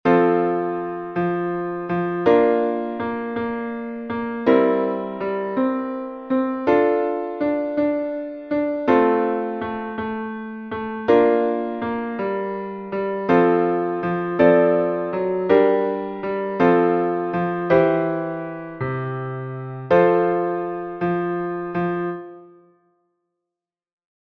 Empregando a seguinte base harmónica, temos que inventar melodías nas que introduciremos as notas de adorno traballadas.
baseimpro.mp3